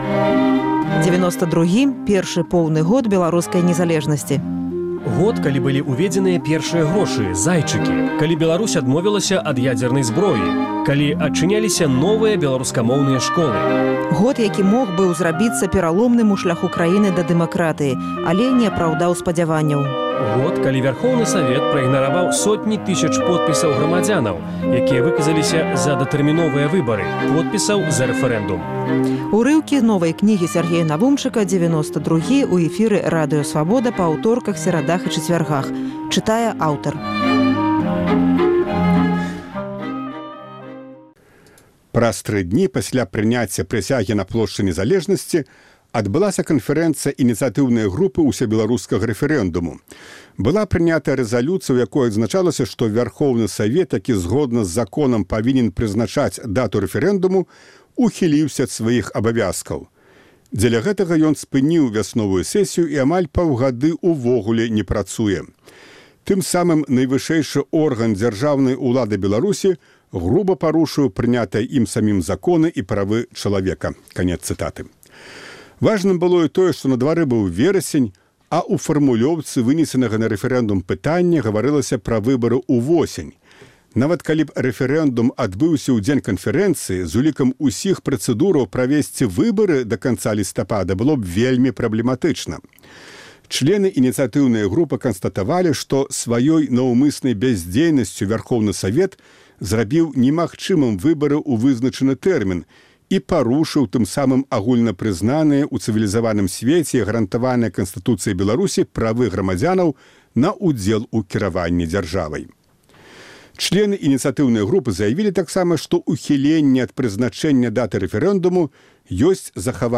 Фрагмэнты новай кнігі пра рэфэрэндум, які не адбыўся. Чытае аўтар